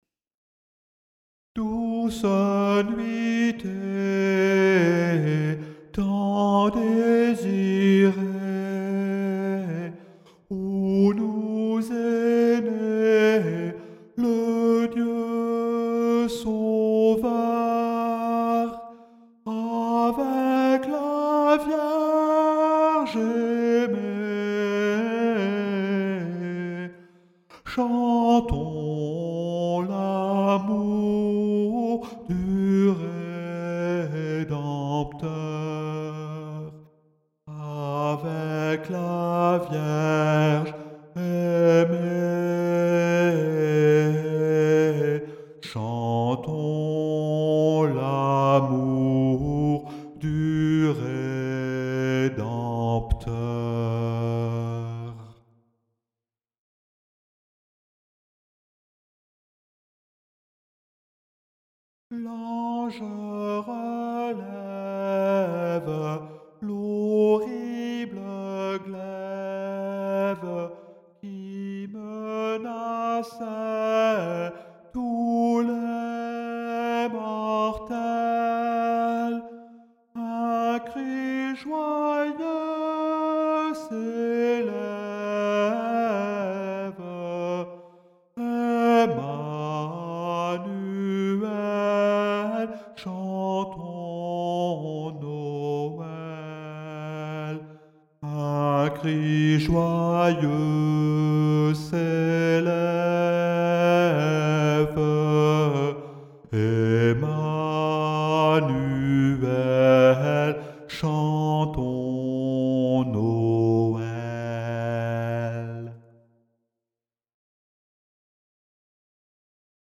vocal       -       piano
01_noel_alto.mp3